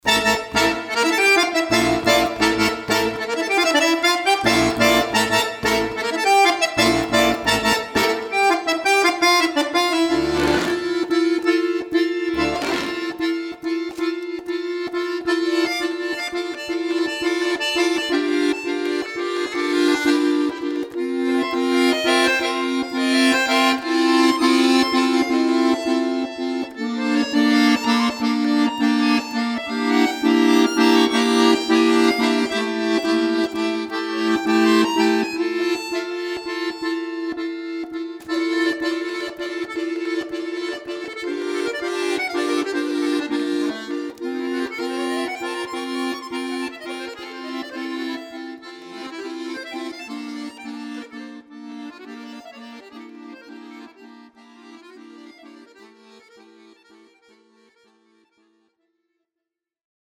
Zeitgenössische Musik / Jazz